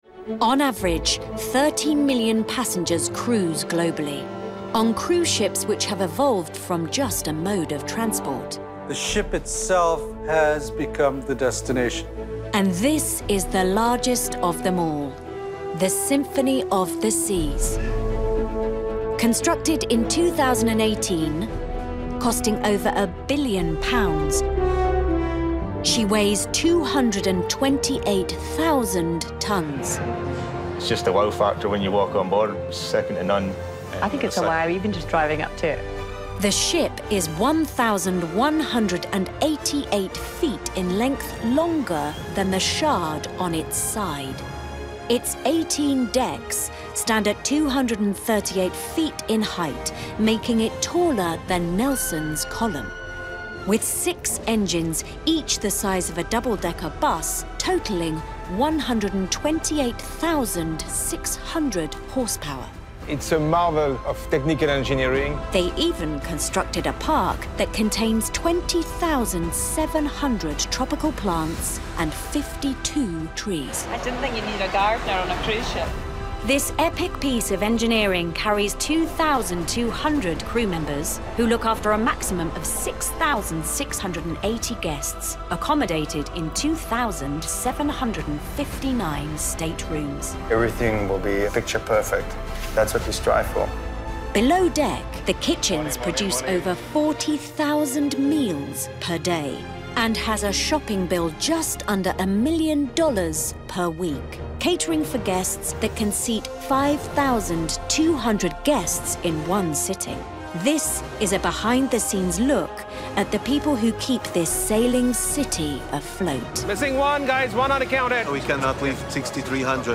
40's Neutral/London,
Warm/Reassuring/Calm